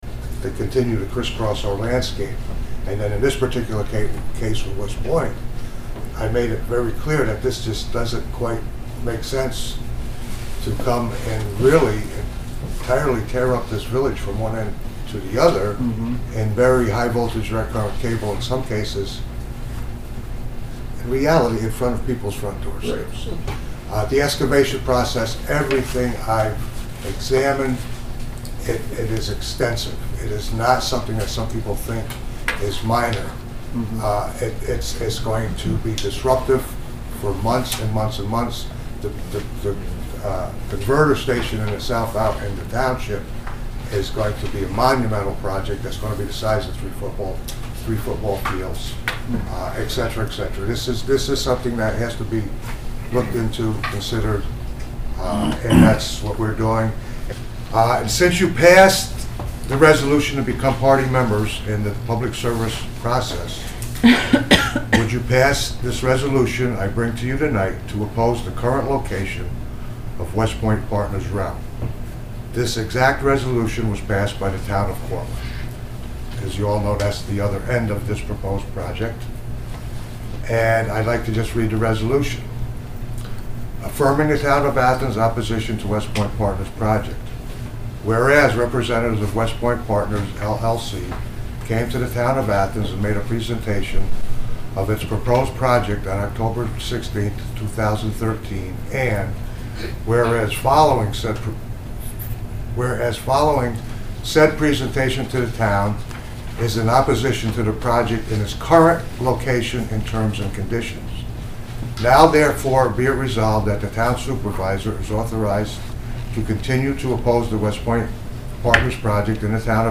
At the meeting of the Athens Town Board, Mon., Nov. 18, the newly established Athens Citizens Development Committee addressed the board with concerns over five power line proposals currently before the New York State Public Service Commission, which would affect the town and village.